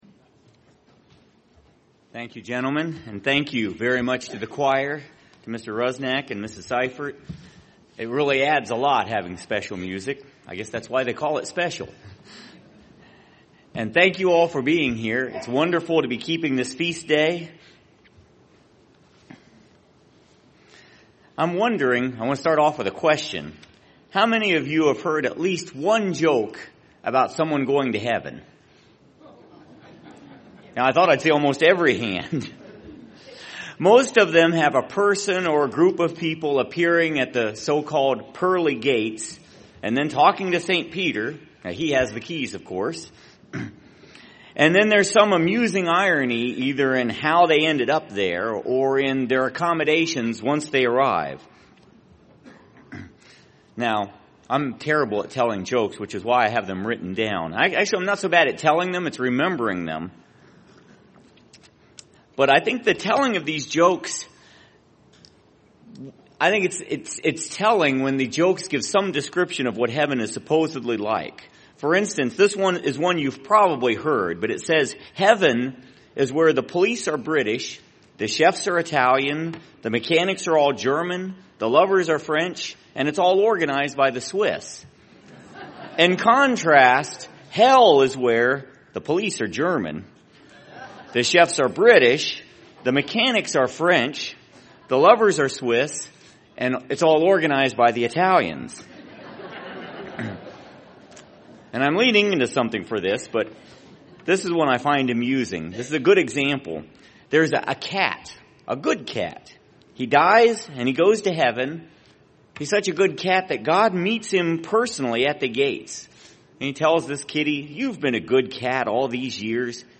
This sermon was given at the Cincinnati, Ohio 2015 Feast site.